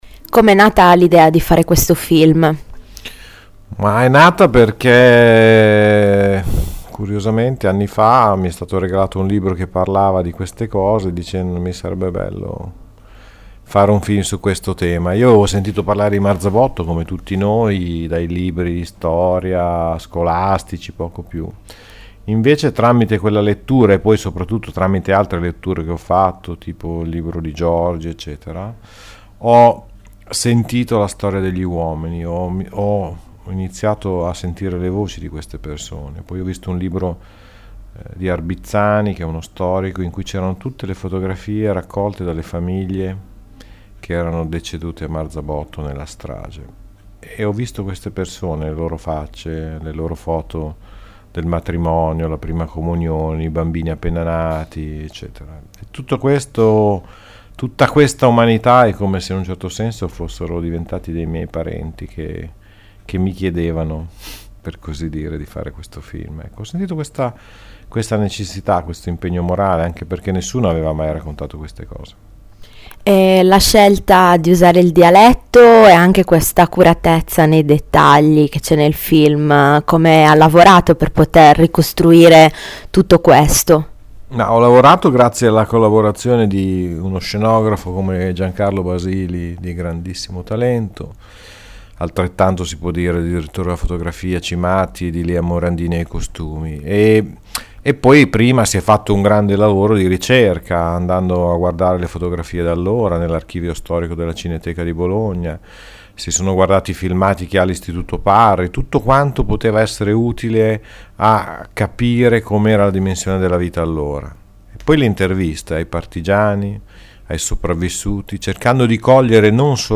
Ascolta l’intervista al regista bolognese